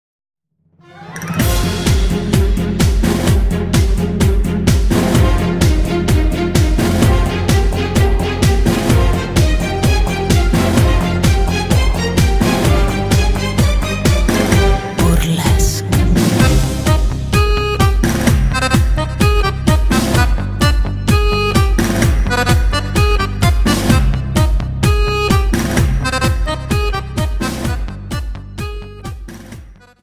Dance: Tango 32